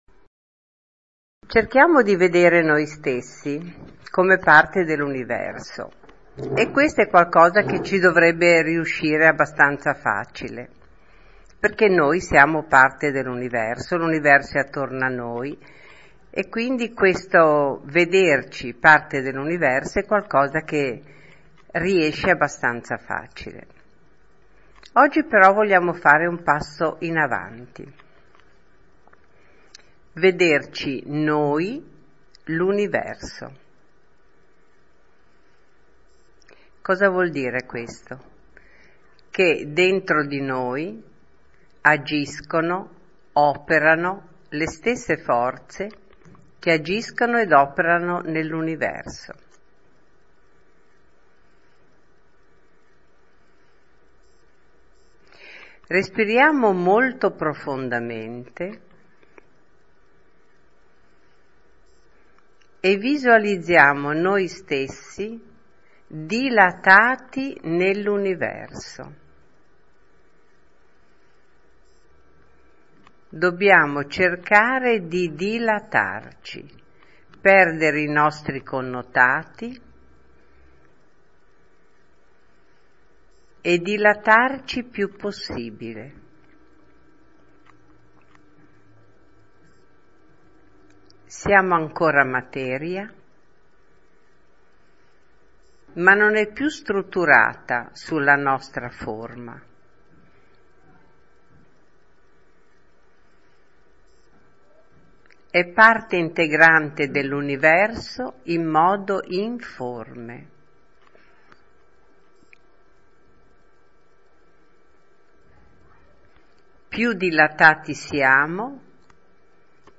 Noi Universo – meditazione
Noi-Universo-meditazione.mp3